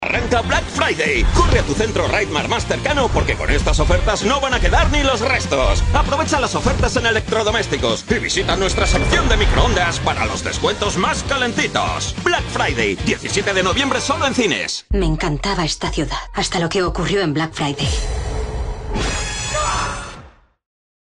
Male
Madrid nativo
Microphone: Neumann Tlm 103